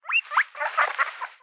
Звук морской свинки (короткий)
Тут вы можете прослушать онлайн и скачать бесплатно аудио запись из категории «Животные, звери».